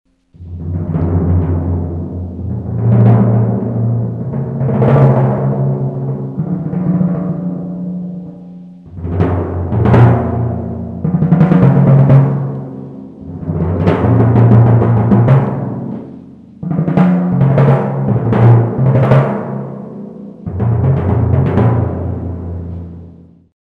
15 слайд. Барабаны литавры сольныйФрагм